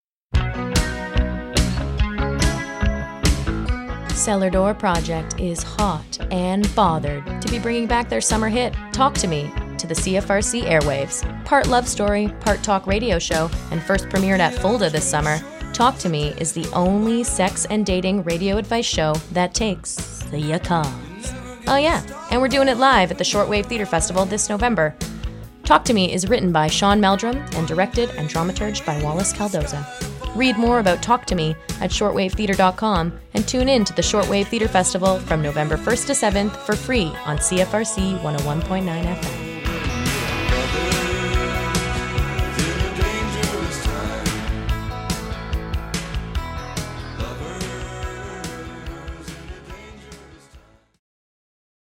Arts and Culture > Radio Arts > Radio Plays